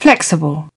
uk-flexible.mp3